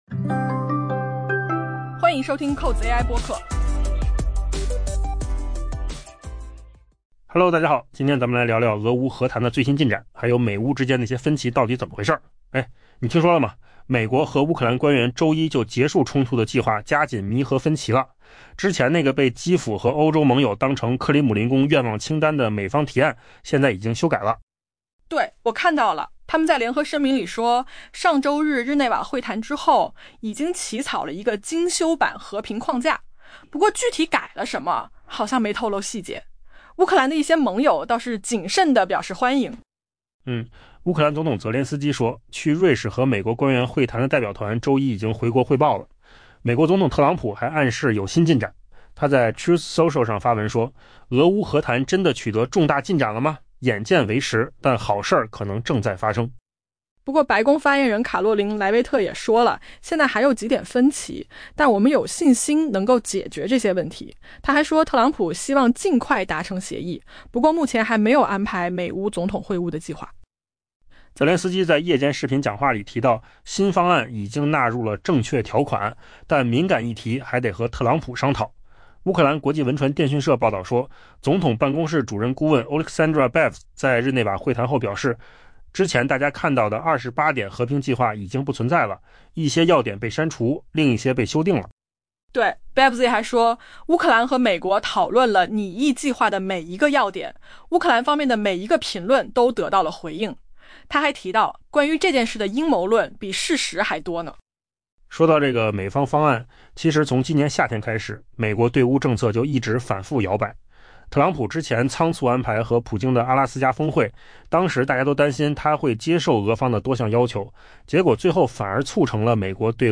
【文章来源：金十数据】AI播客：换个方